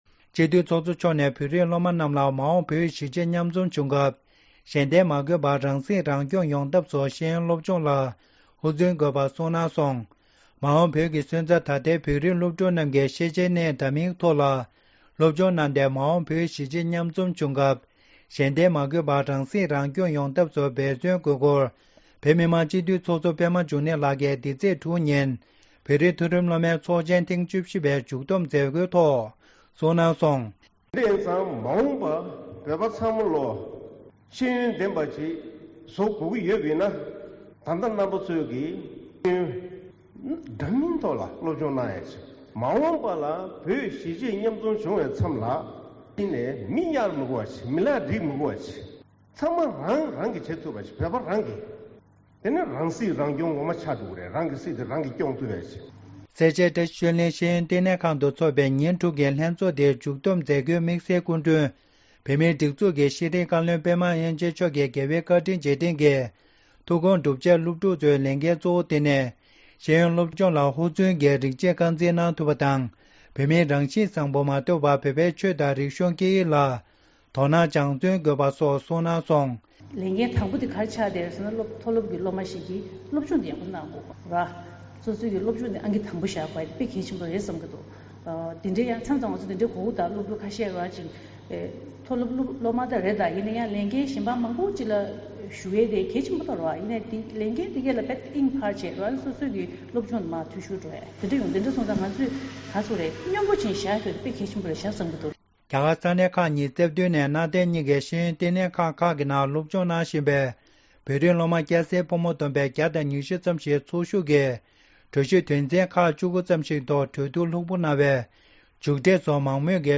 ཤེས་ཡོན་ཐོག་ཧུར་བརྩོན་དགོས་པའི་ལམ་སྟོན། ཚོགས་གཙོ་པདྨ་འབྱུང་གནས་མཆོག་ནས་སྐབས་བཅུ་བཞི་པའི་བོད་ཀྱི་མཐོ་རིམ་སློབ་མའི་ཚོགས་ཆེན་ཐོག་གསུང་བཤད་གནང་བཞིན་པ། ༣།༢༠༢༠
སྒྲ་ལྡན་གསར་འགྱུར། སྒྲ་ཕབ་ལེན།